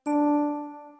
There are six different pitches used in the melody.